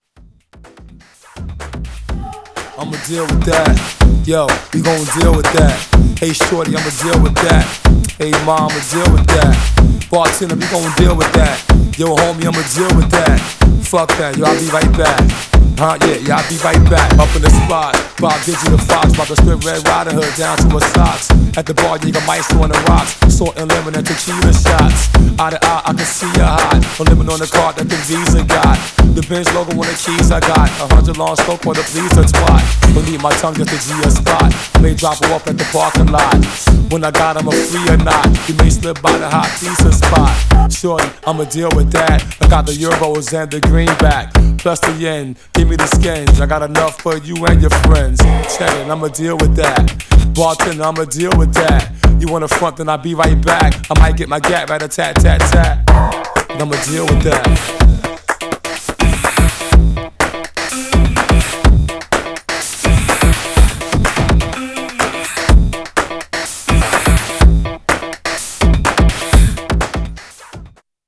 Styl: Hip Hop, House, Breaks/Breakbeat